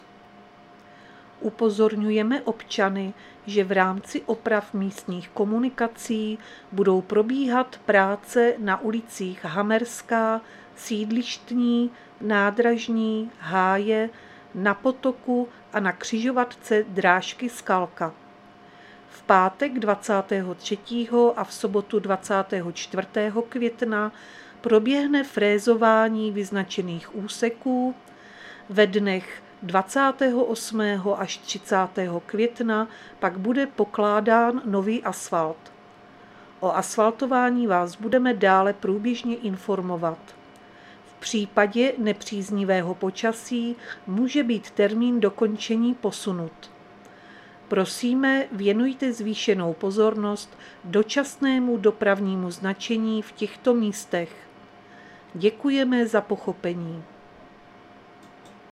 Záznam hlášení místního rozhlasu 22.5.2025
Zařazení: Rozhlas